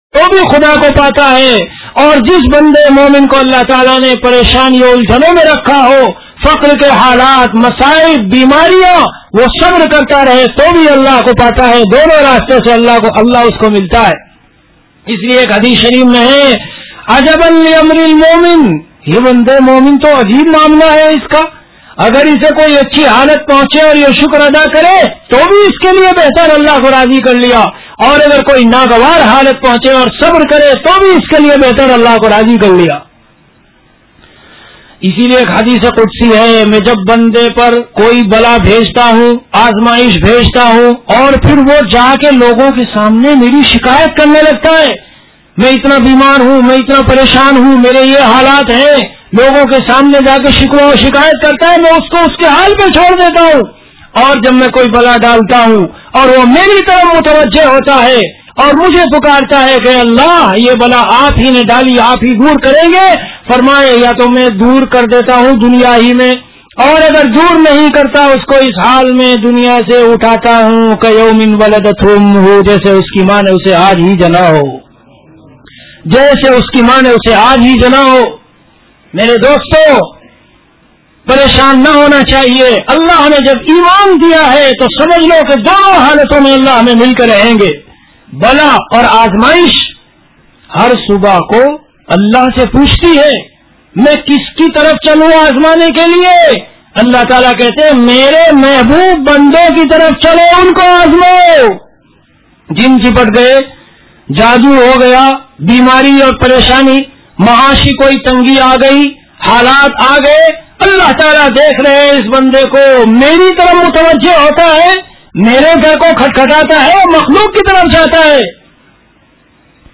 Delivered at Khanqah Imdadia Ashrafia.
Bayanat · Khanqah Imdadia Ashrafia